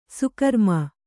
♪ sukarma